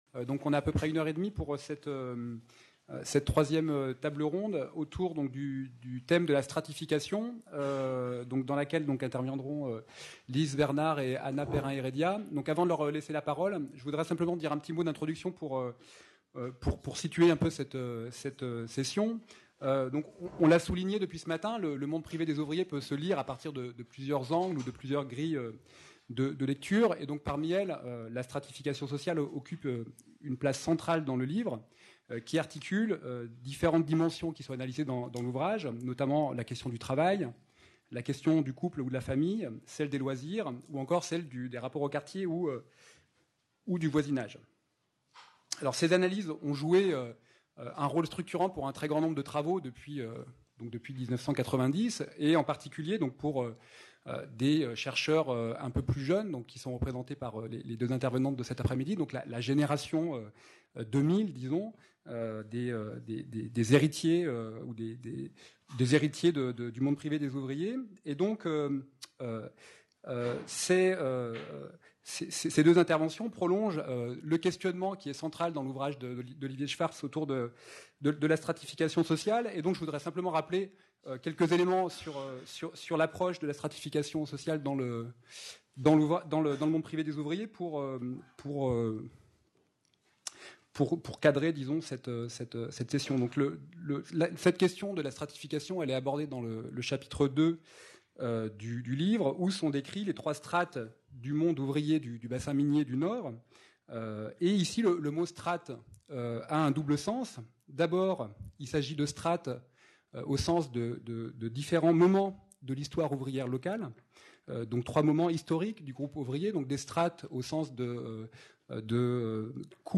Table-ronde 3 : Nouvelles perspectives sur la stratification